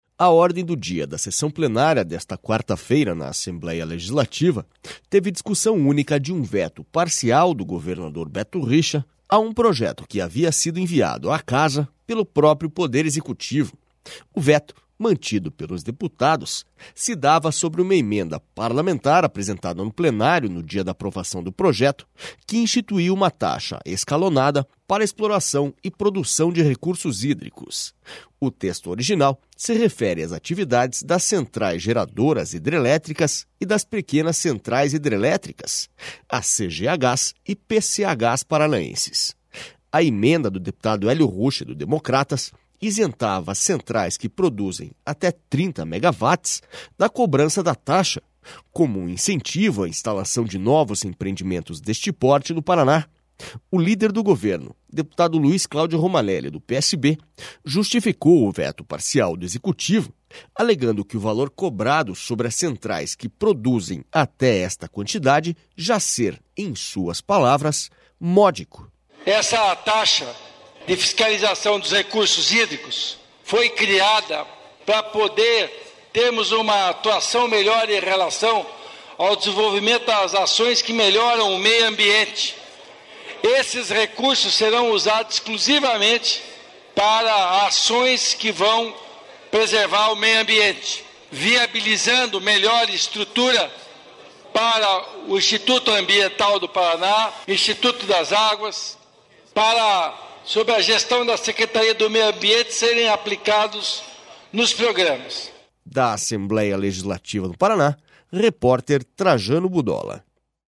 SONORA LUIZ CLAUDIO ROMANELLI